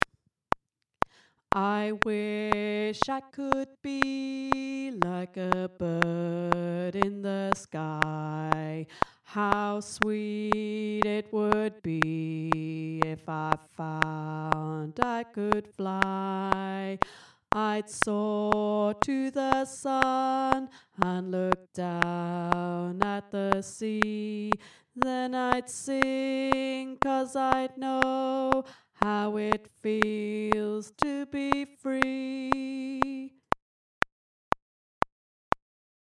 I Wish I Knew Tenor